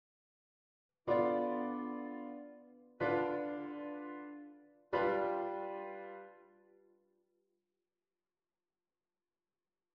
dissonantie in VII6